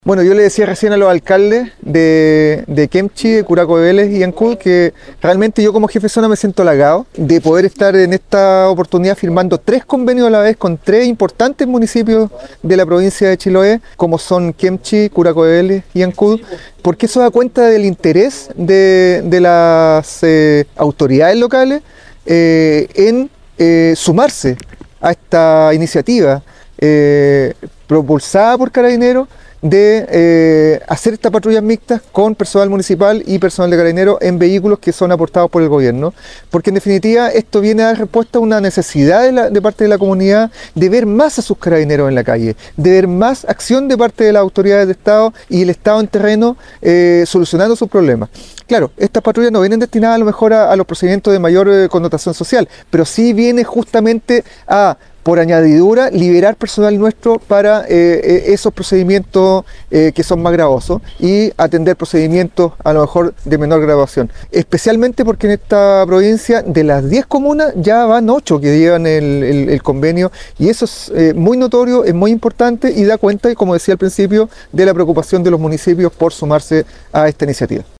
En tanto, el General de Carabineros Héctor Valdés, señaló: